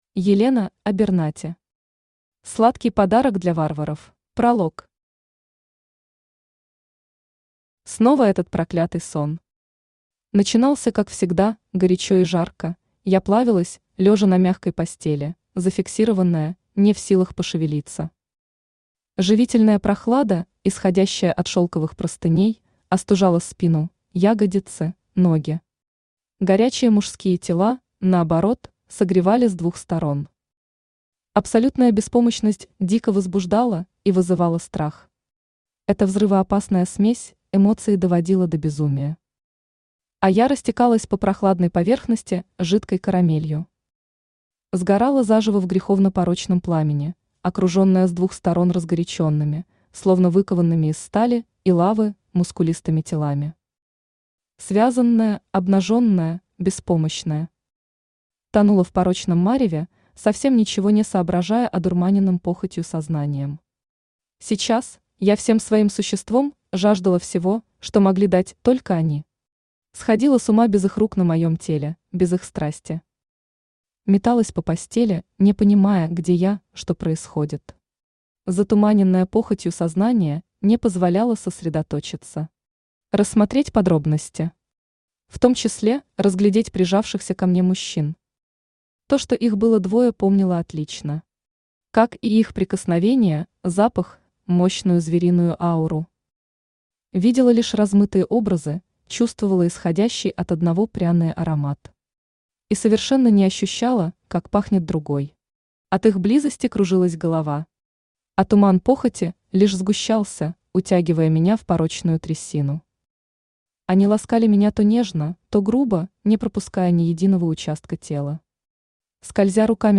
Aудиокнига Сладкий подарок для варваров Автор Елена Абернати Читает аудиокнигу Авточтец ЛитРес.